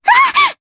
Mediana-Vox_Damage_jp_02.wav